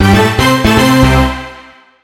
Talk Show Theme Orchestra Fill